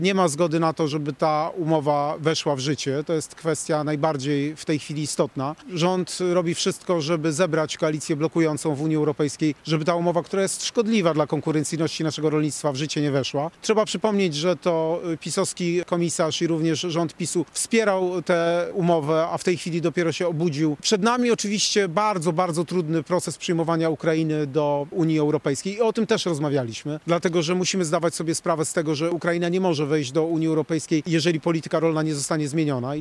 Z rolnikami i samorządowcami z województwa zachodniopomorskiego spotkał się dzisiaj prezydent Warszawy i kandydat na prezydenta Polski w jednym, czyli Rafał Trzaskowski.